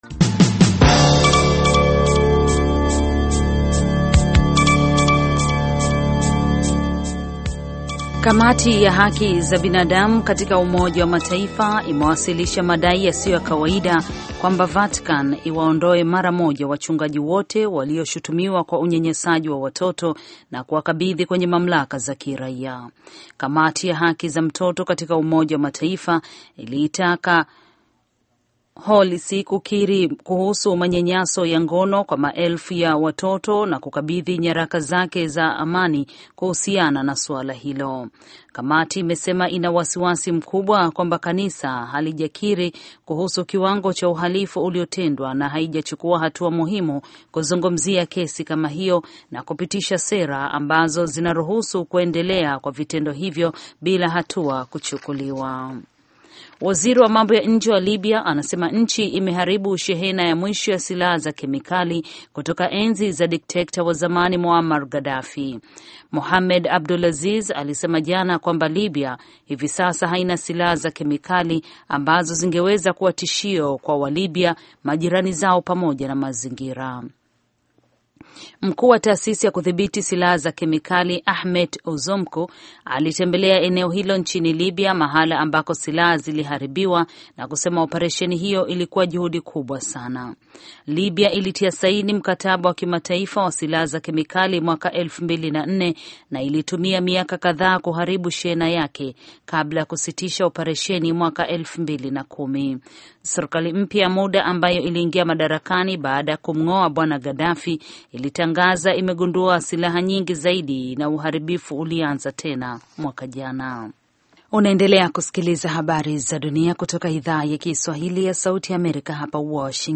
Taarifa ya Habari VOA Swahili - 6:37